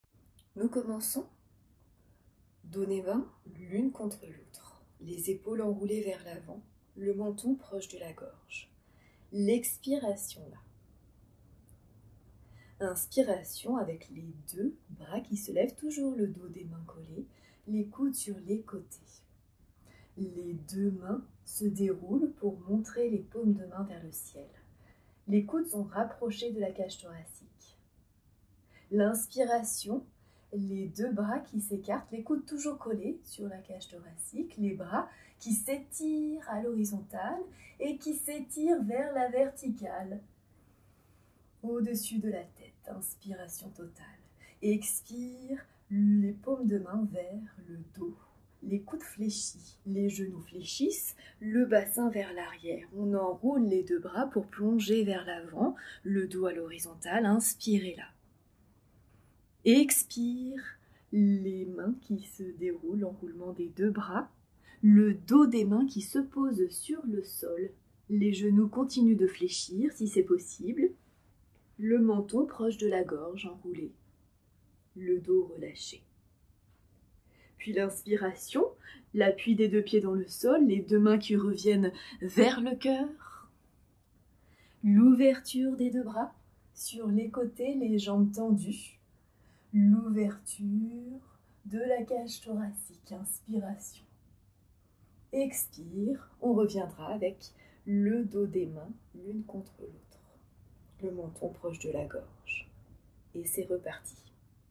Salutation fluide guidée
salutation-fluide-guidee.mp3